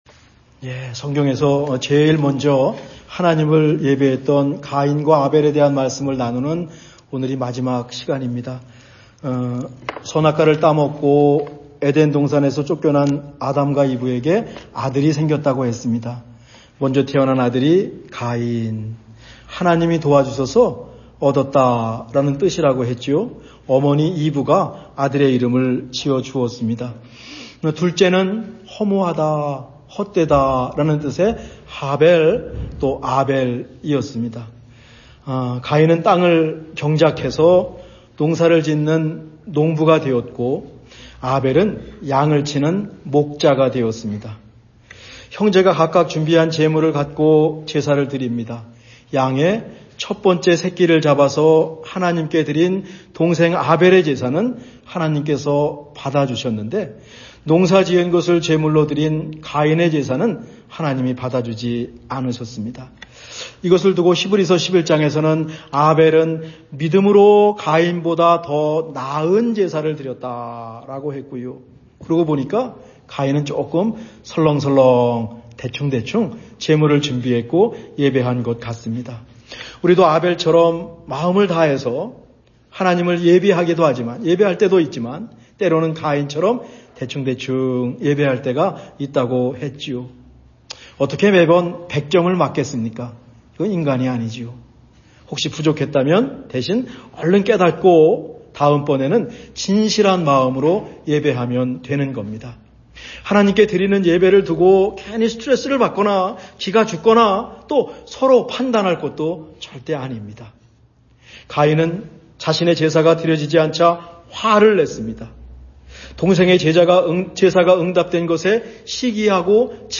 2026년 3월 1주 말씀